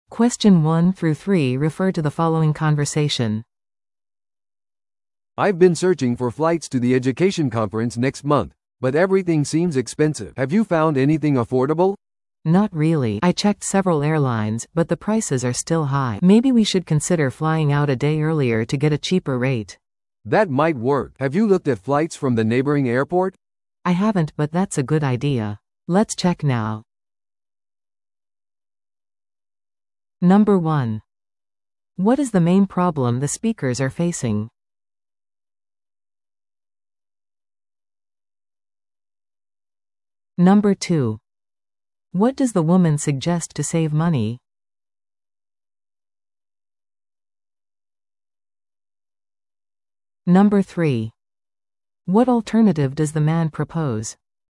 No.2. What does the woman suggest to save money?
No.3. What alternative does the man propose?